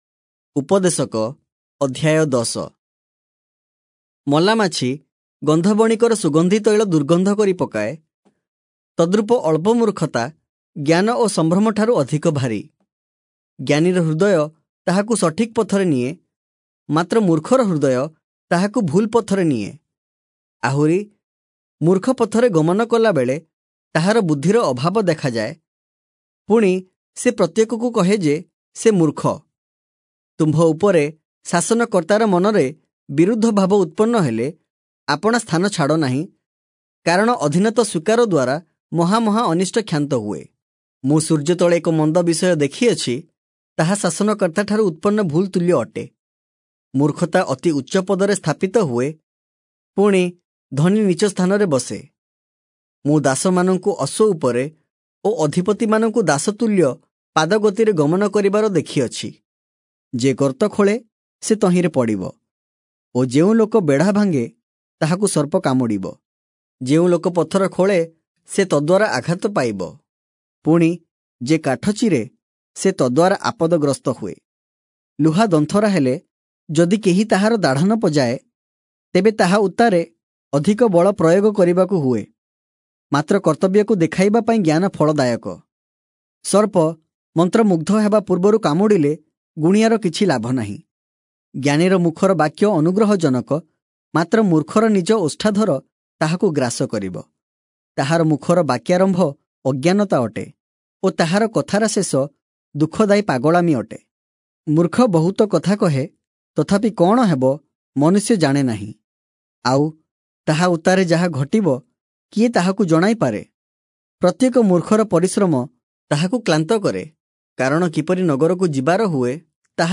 Oriya Audio Bible - Ecclesiastes 6 in Irvor bible version